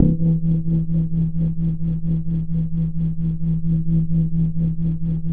88 MACHINE-R.wav